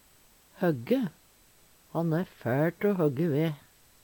DIALEKTORD PÅ NORMERT NORSK høgge hogge Infinitiv Presens Preteritum Perfektum høgge høgg høgg højje Eksempel på bruk Han e fæL te o høgge ve.